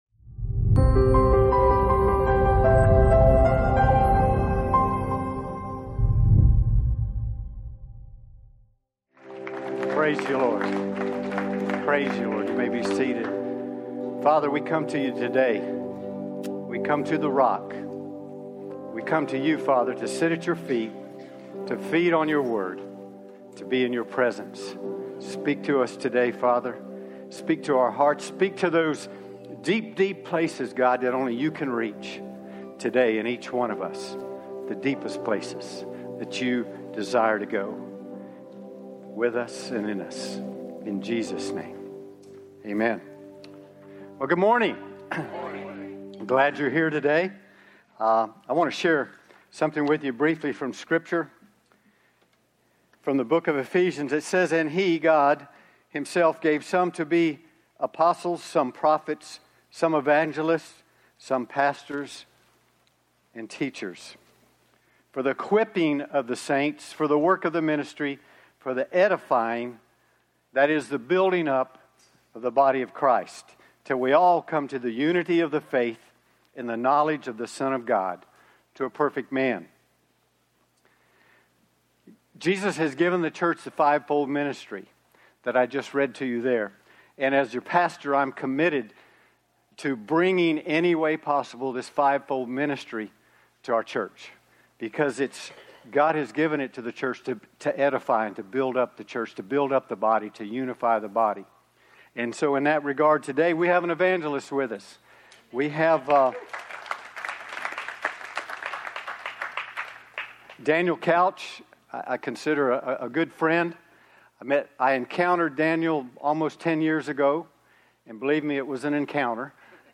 9 AM Sermon